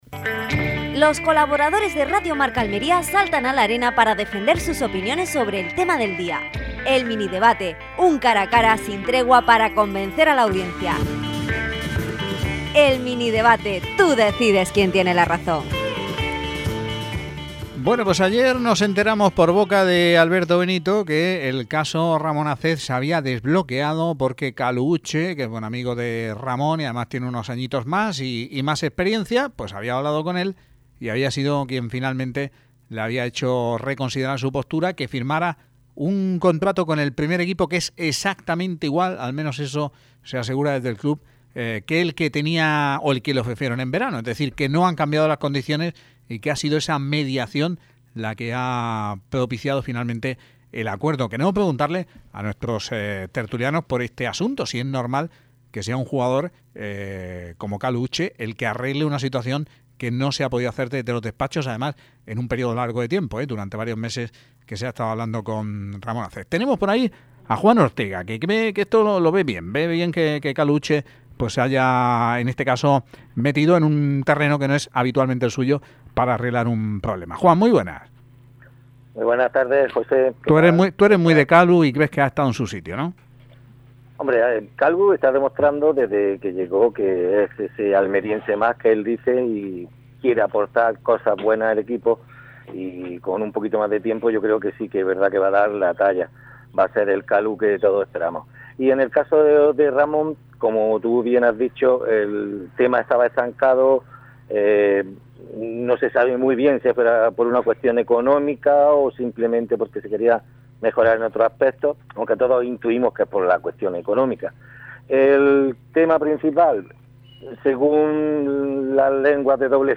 MINI DEBATE 28-01-16